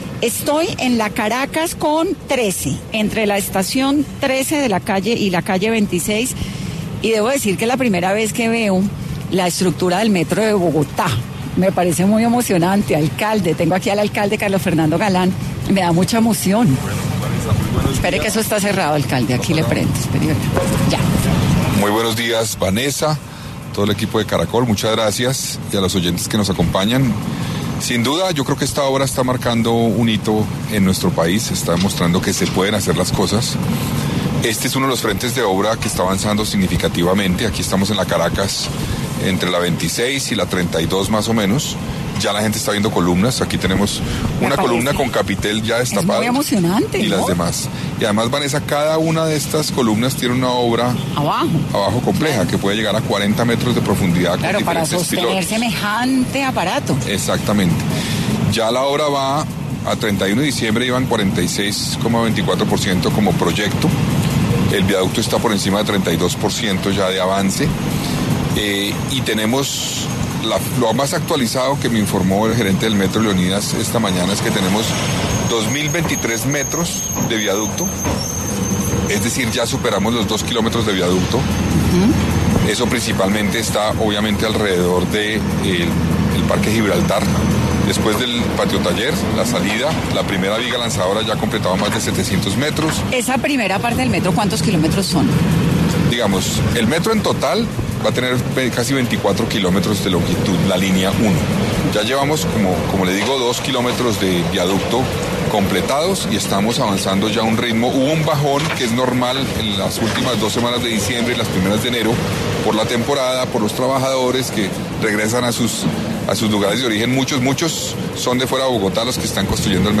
En Caracol Radio estuvo el alcalde de la capital conversando con Vanessa de la Torre para hacer un balance de su primer año de gestión